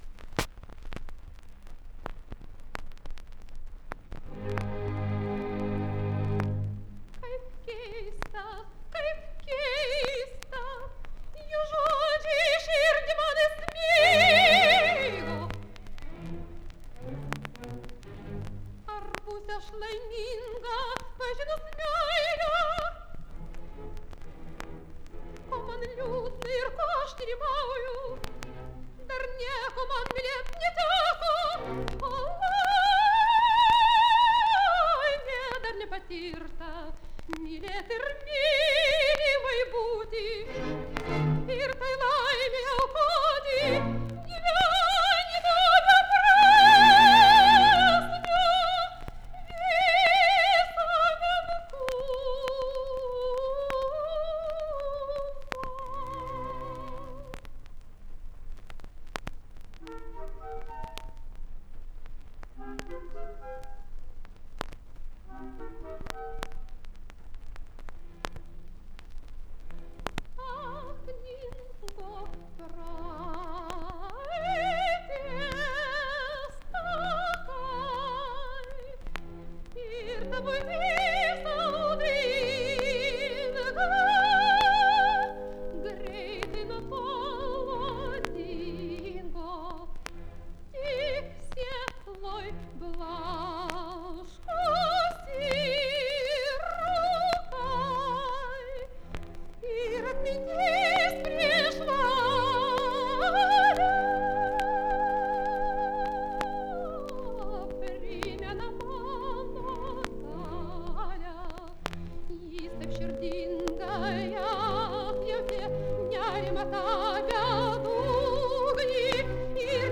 LP Operų ištraukos